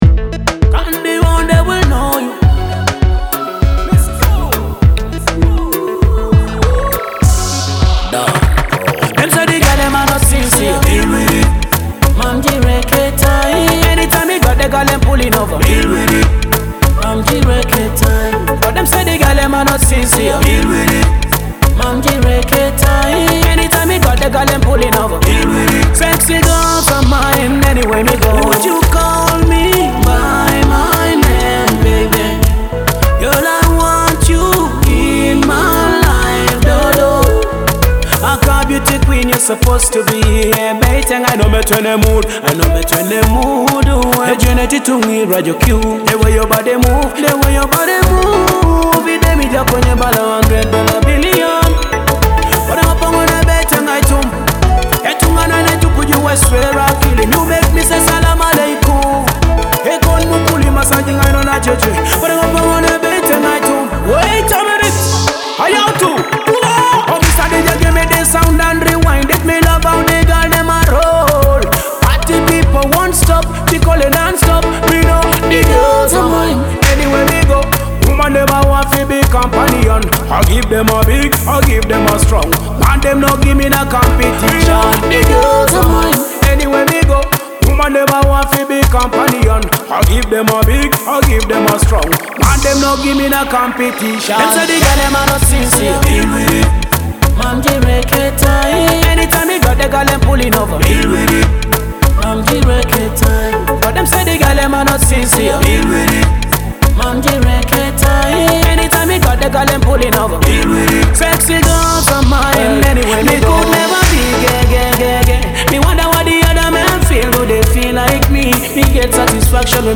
a powerful new track packed with energy and attitude.
romantic love dancehall